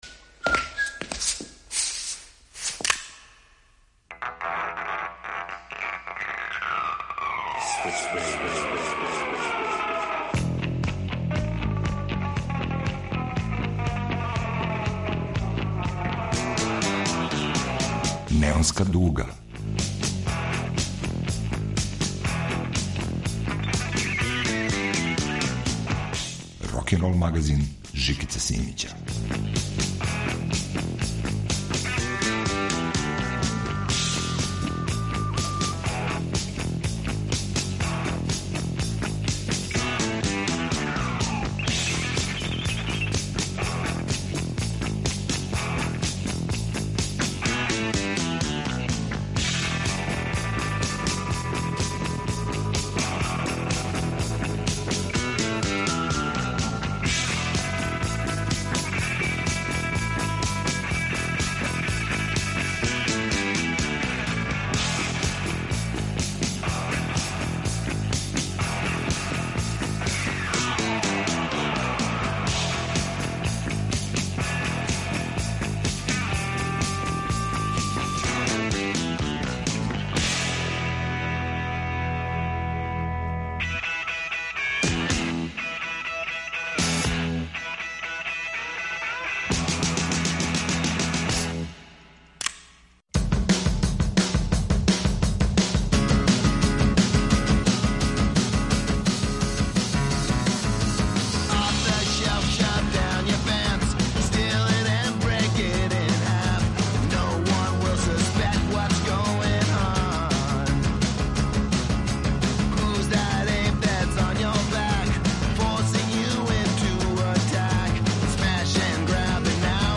Данас је на програму трансжанровско издање Неонске дуге.
На репертоару је више од двадесет песама ‒ од гаражног рока до контемплативног фолка.